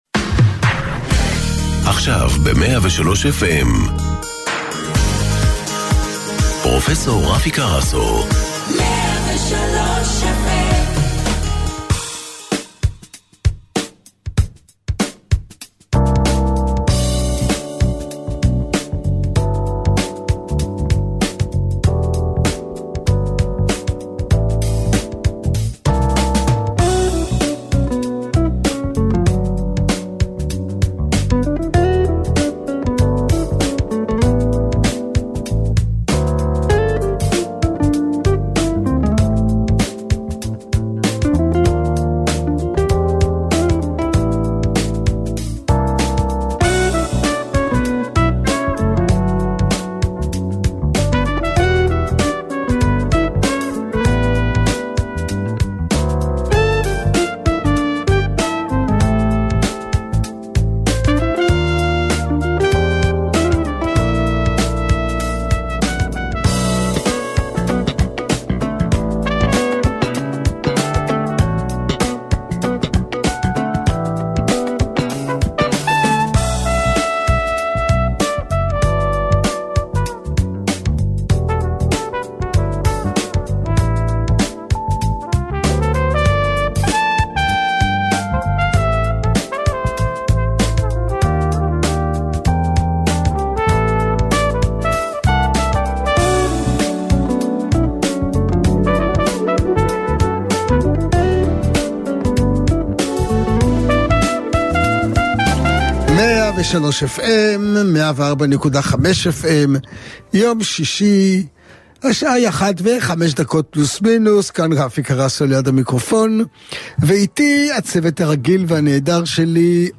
תוכנית הרדיו של פרופסור רפי קרסו היא שליחות. בכל שישי בצהריים הוא כאן כדי לענות על כל שאלה שמעסיקה אתכם בנושאי בריאות הגוף והנפש.